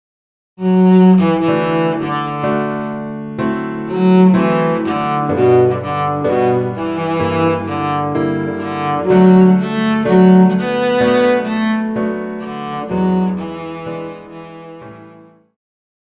Pop
Cello
Band
Instrumental
Rock,Country
Only backing